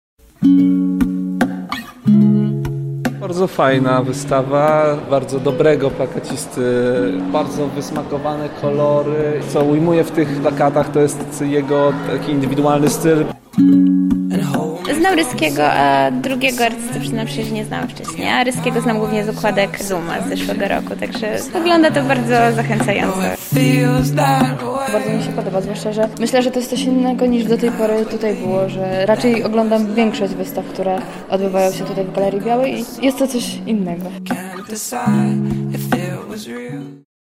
Podwójny wernisaż zgromadził duże grono miłośników sztuki.
wernisaż